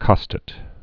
(kŏstət, kôstāt)